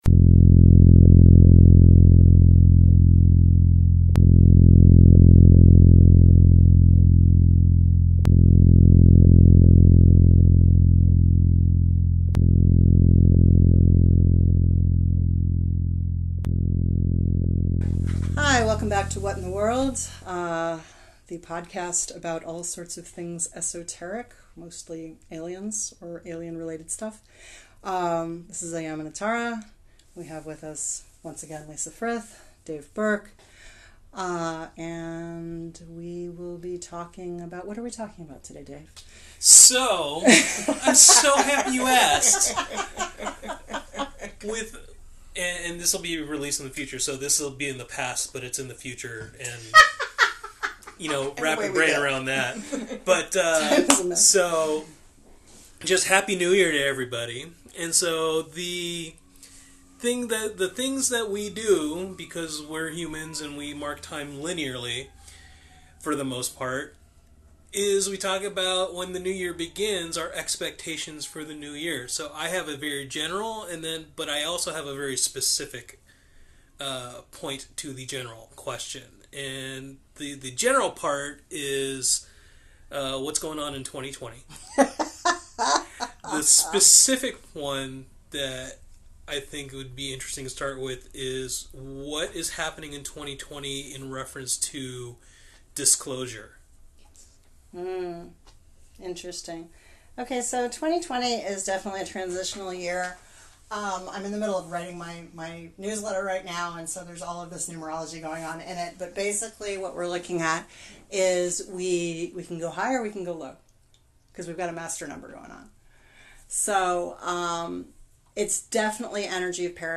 Intro and Outro are a recording of a Keppler star, courtesy of NASA